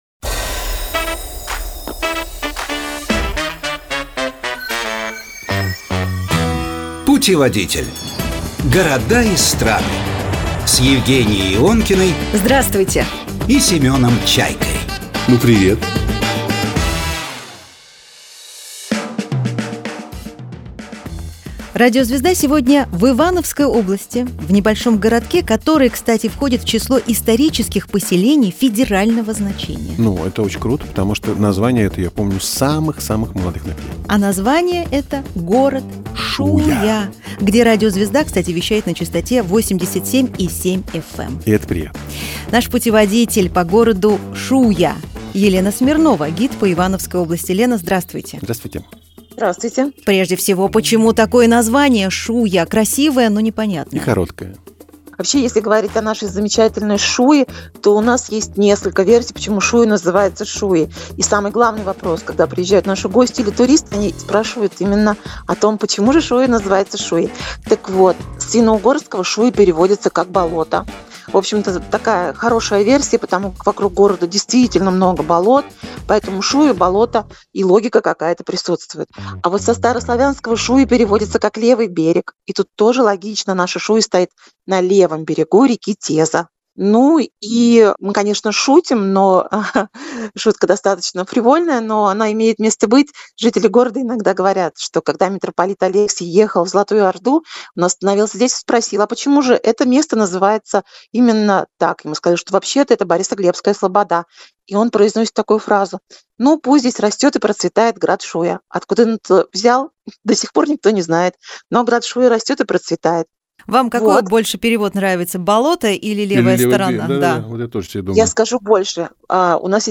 В студии программы «Путеводитель»